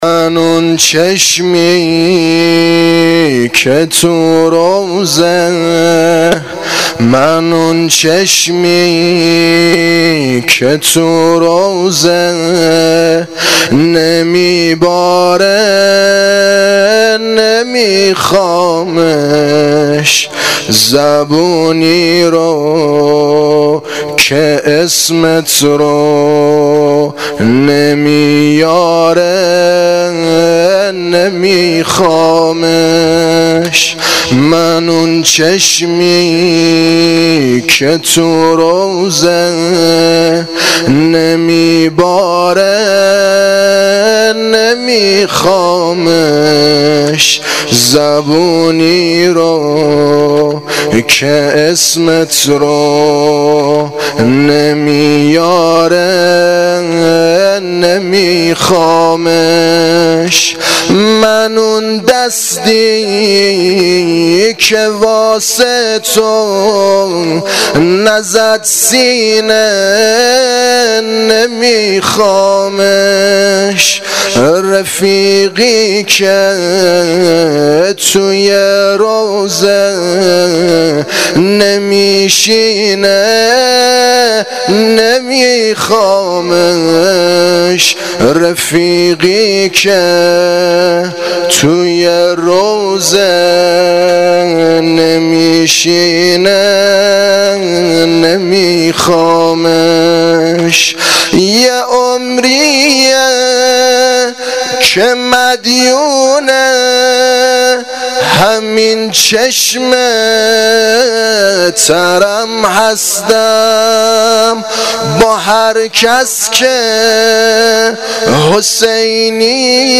واحد شب پنجم محرم الحرام 1396